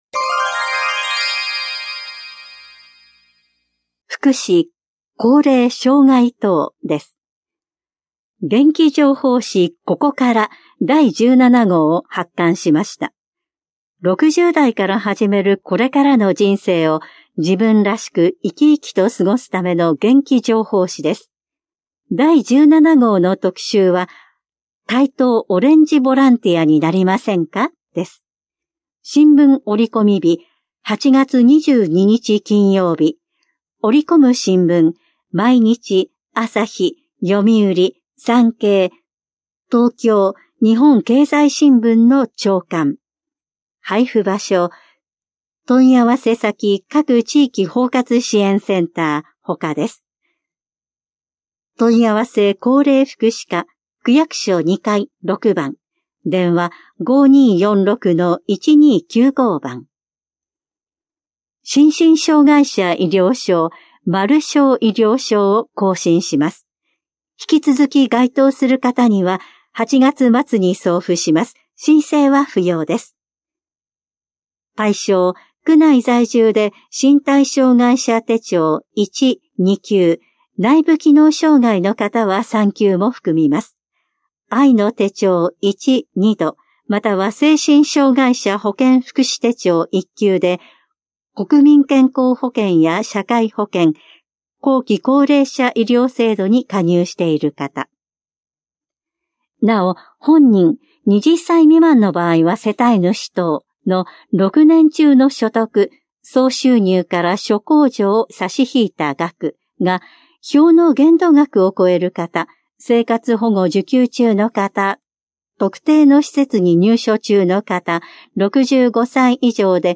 広報「たいとう」令和7年8月20日号の音声読み上げデータです。